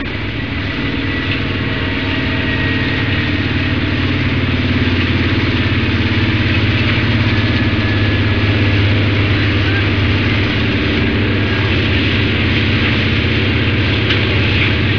دانلود صدای طیاره 58 از ساعد نیوز با لینک مستقیم و کیفیت بالا
جلوه های صوتی
برچسب: دانلود آهنگ های افکت صوتی حمل و نقل دانلود آلبوم صدای طیاره از افکت صوتی حمل و نقل